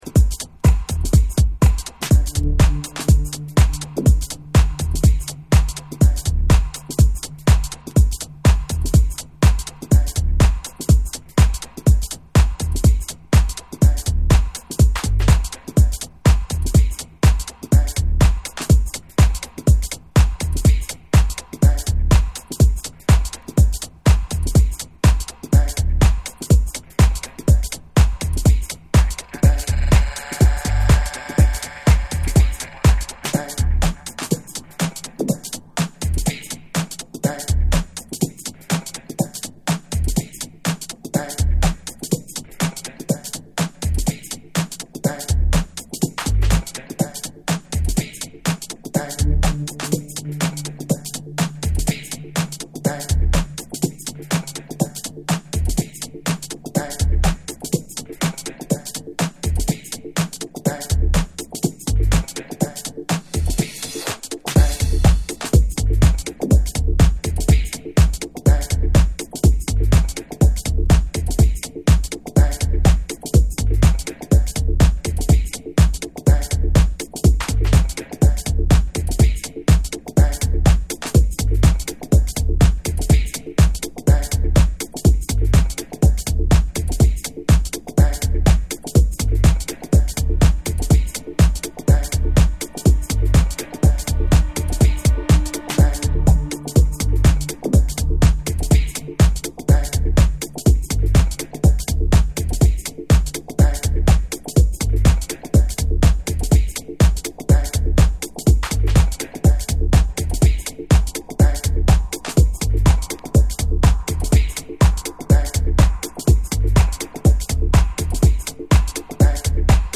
minimal and house cuts